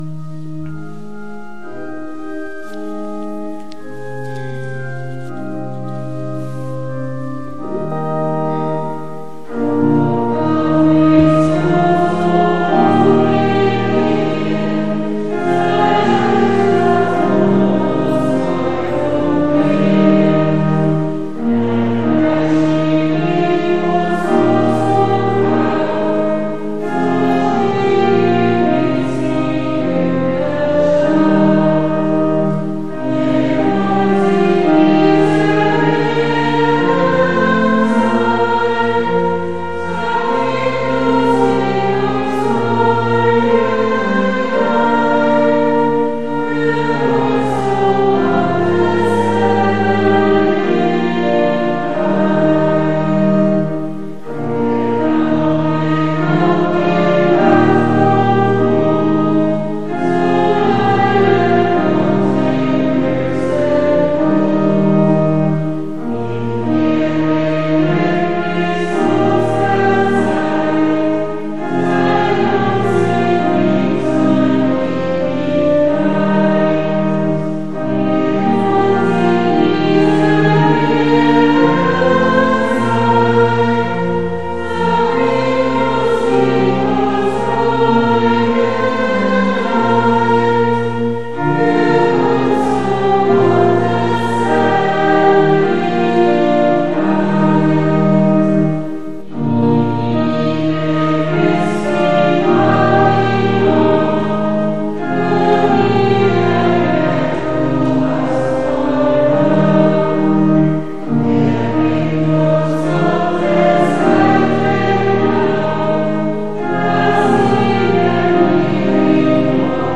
Zum Abschluss sangen alle gemeinsam das Lied vom Schutzpatron St. Vitus.